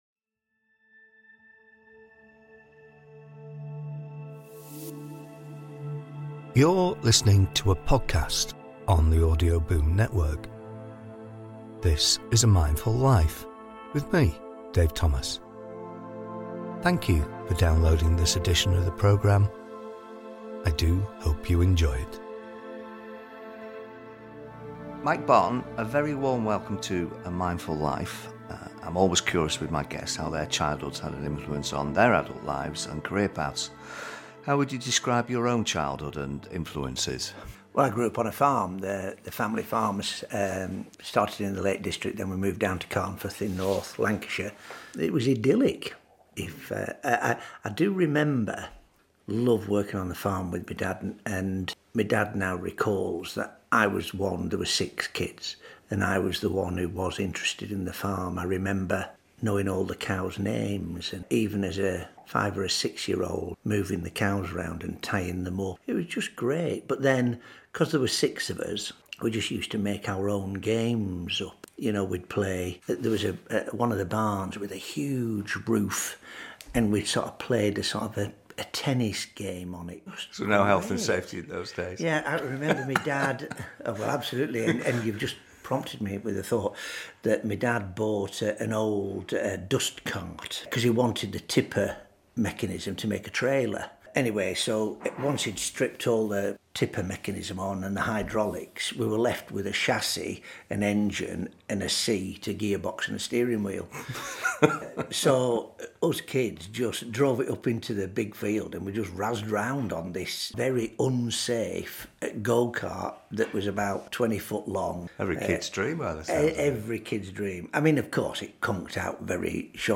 In this candid interview he talks about his police career and his passion for life.